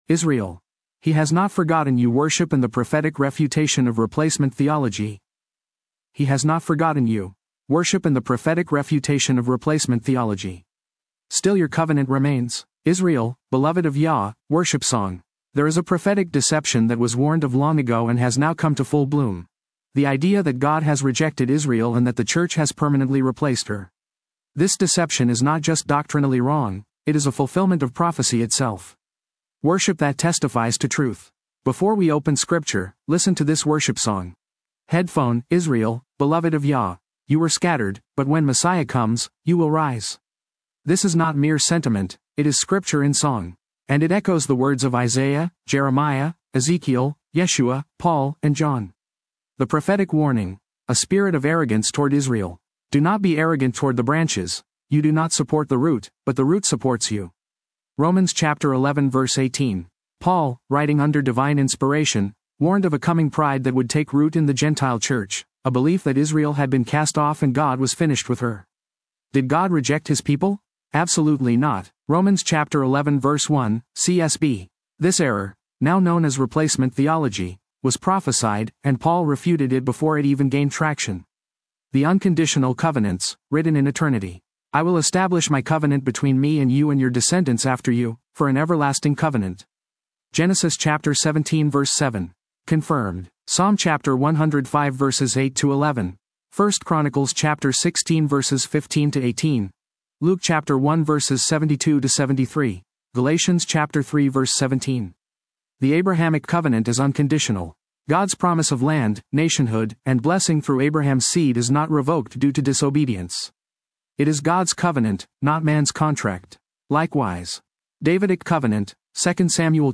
(worship song)